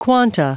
Prononciation du mot quanta en anglais (fichier audio)
Prononciation du mot : quanta